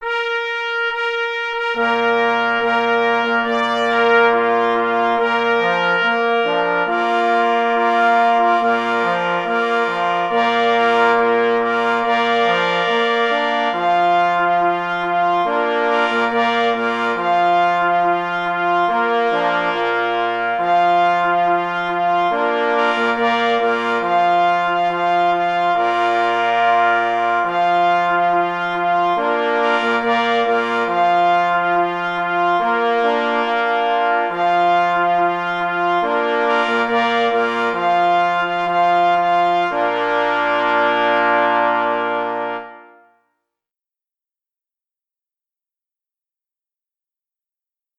Opis zasobu: rodzaj marsza wolnego, czyli żałobnego na 3 […]
K. Anbild – Marsz żałobny – na 3 plesy i 2 parforsy | PDF